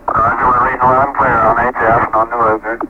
capcom_launch_misc_16.wav